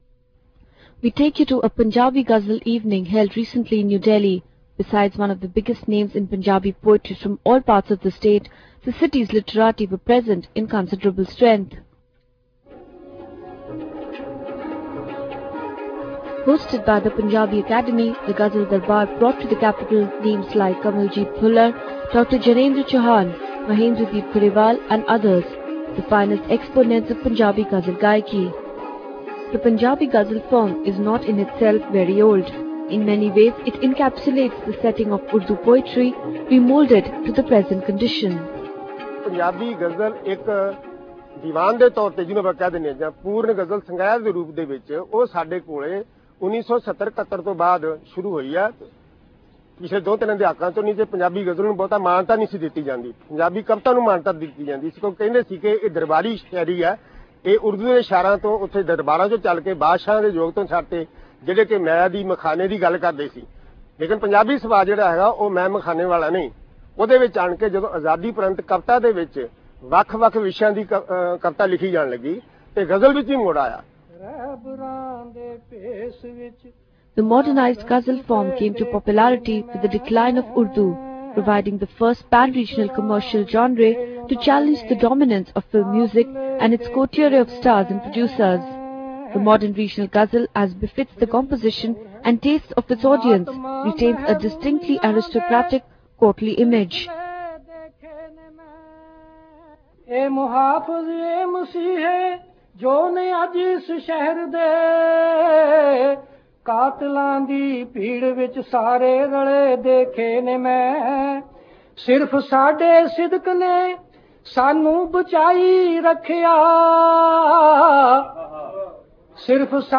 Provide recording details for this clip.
We take you to a Punjabi ghazal evening held recently in New Delhi. Besides some of the biggest names in Punjabi poetry from all parts of the state, the city's literati were present in considerable strength.